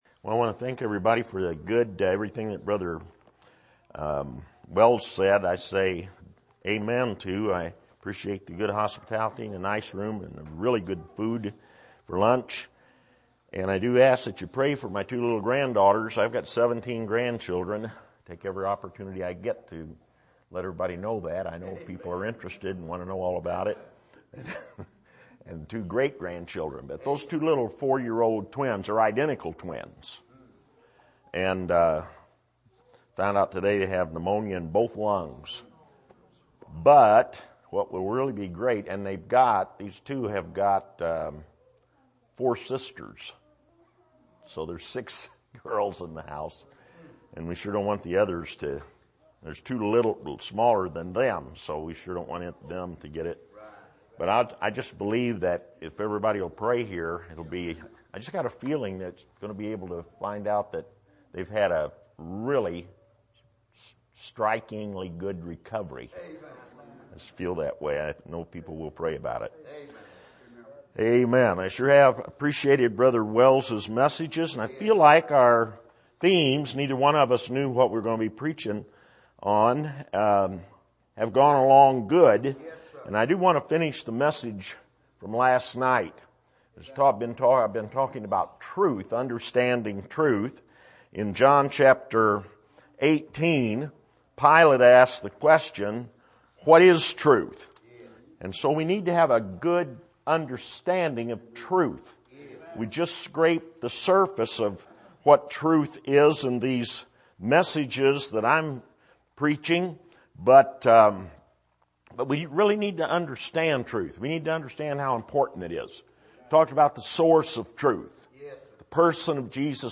Passage: John 18:38 Service: Bible Conference